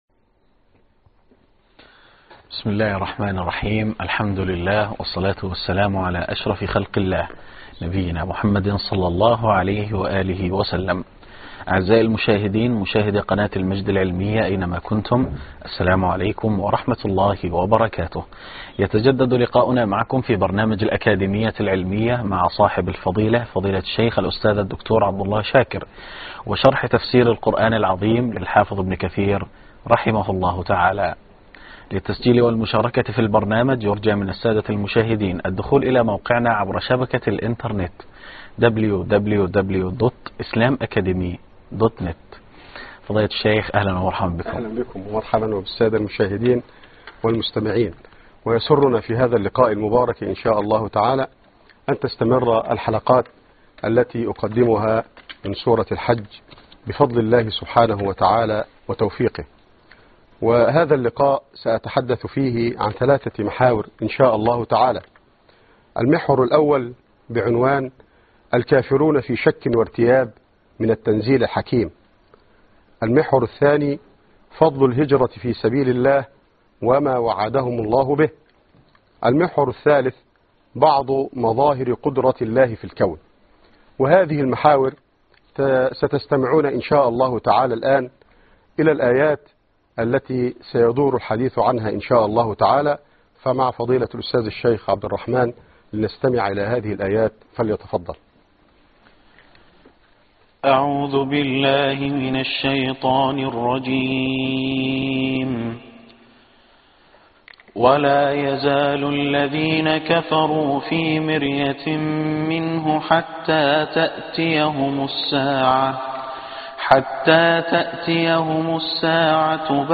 الدرس السابع _ سورة الحج من الآية 55